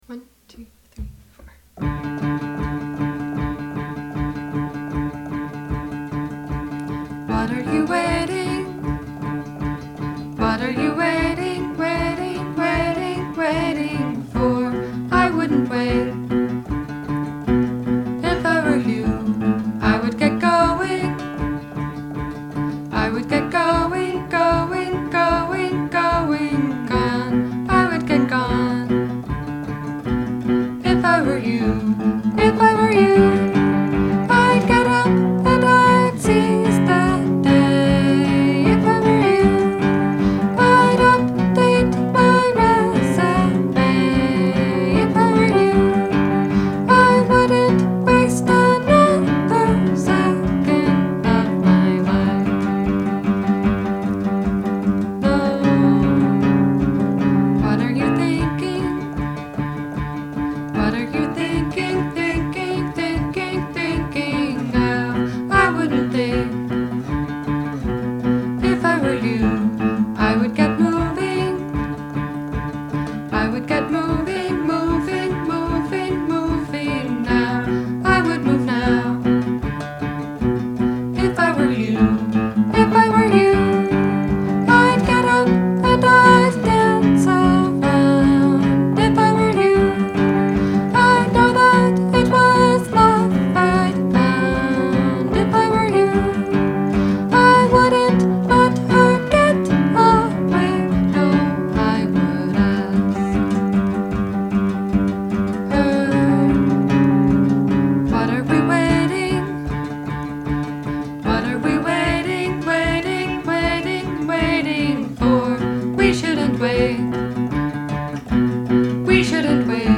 I'm disappointed in this recording because the song is supposed to make you want to get up and dance around.  I think it needs bass drum.
the tri-tone in there--whoa. super gutsy! at first i thought my headphones were broken, but now it's growing on me. i think for jumpiness you just need some kind of hot break beats and maybe electric guitars. i think the melody is sufficiently driving. the repetition is good for that, too. finally, this one hits a little close to home. i soooo need to update my resumé.